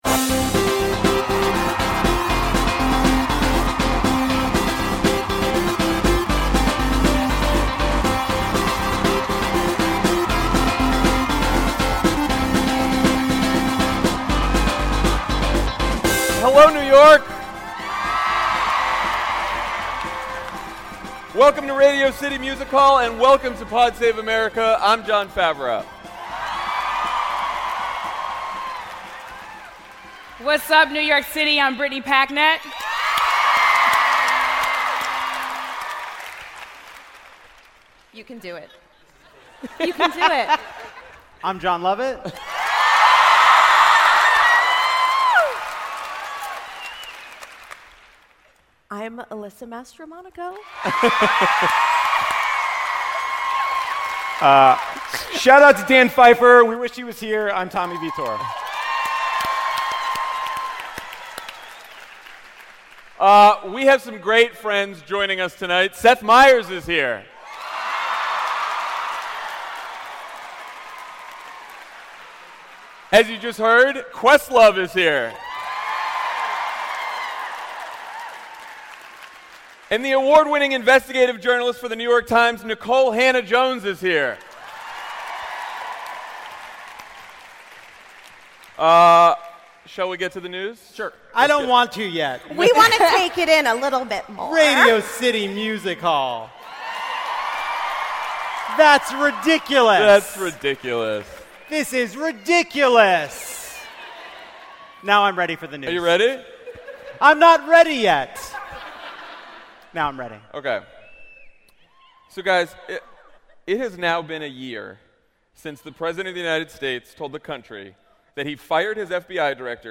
Mueller racks up 75 criminal charges in one year, Trump tries to shut the investigation down, and the White House goes after Planned Parenthood. Then Seth Meyers, Questlove, and the New York Times’ Nikole Hannah-Jones join Jon, Jon, Tommy, Brittany Packnett, and Alyssa Mastromonaco on stage at Radio City Music Hall in New York City.